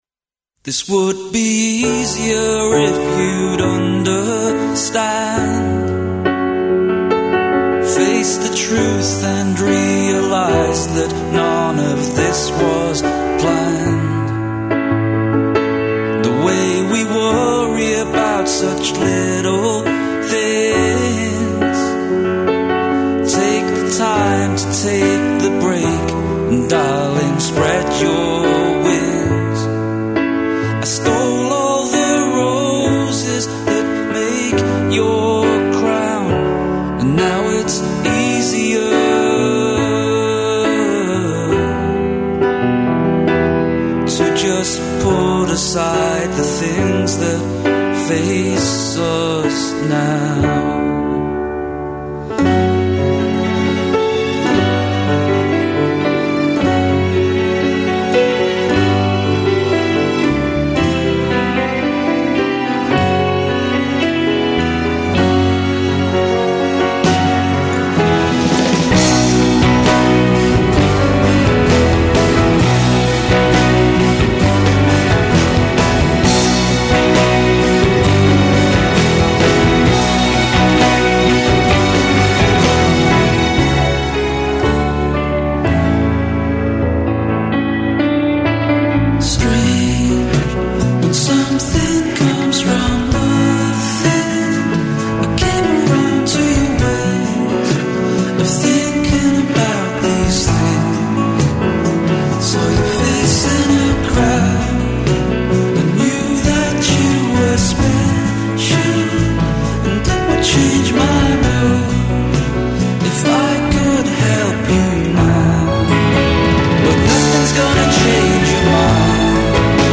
Гост емисије је Драгољуб Ђуричић, бубњар.